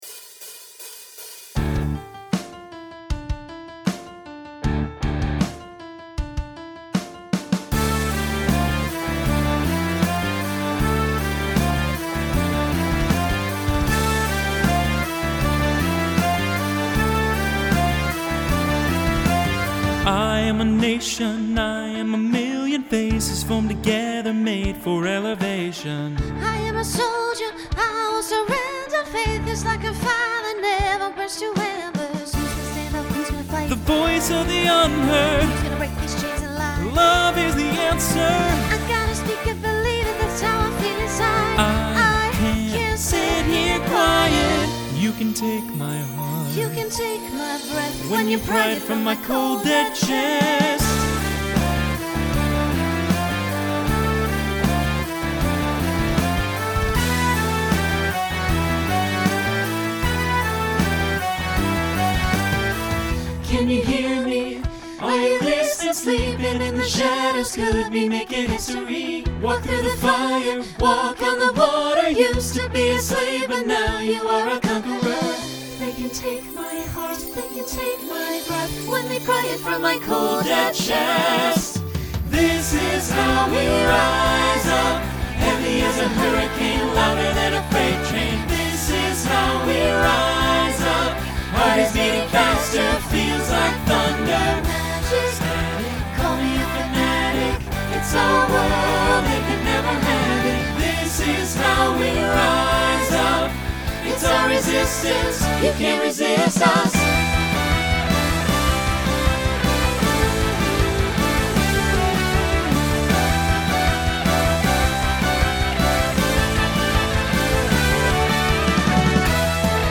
Rock
Story/Theme Voicing SATB